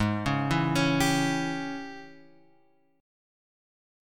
G#M7sus4#5 chord {4 4 2 x 2 3} chord